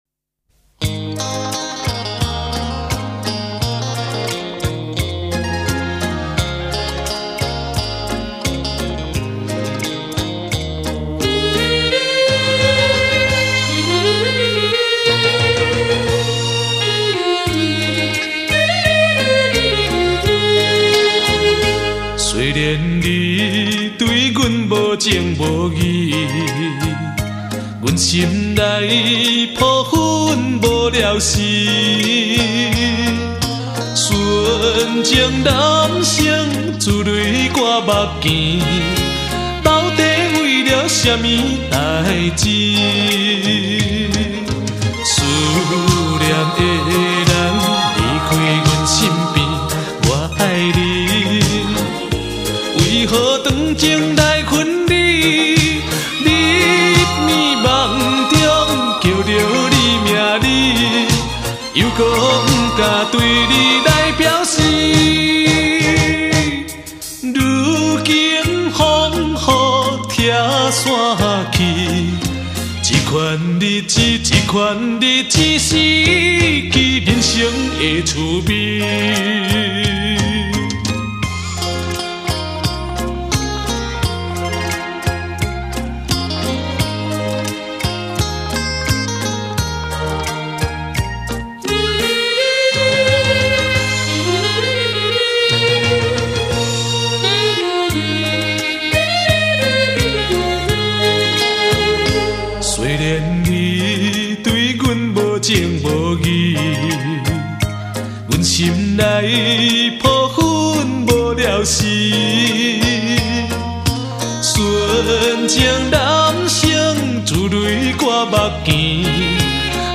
低品质